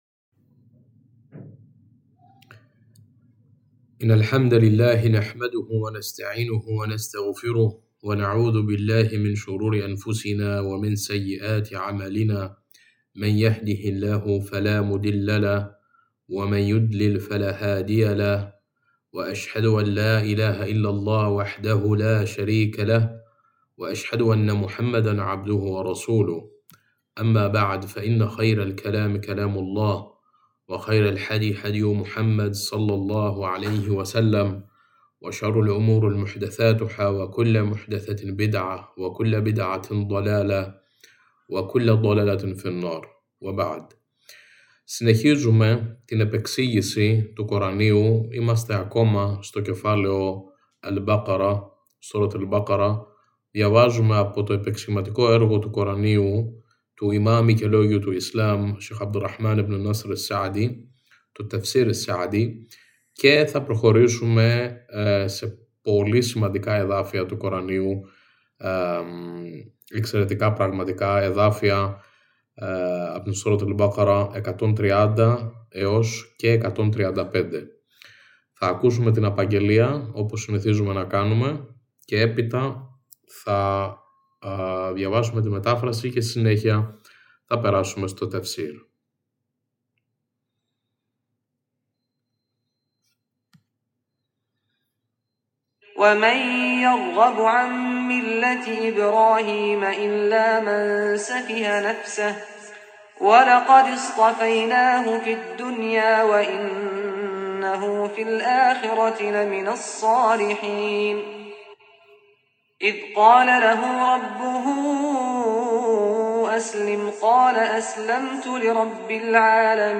Ανάγνωση, Μετάφραση και Παρουσίαση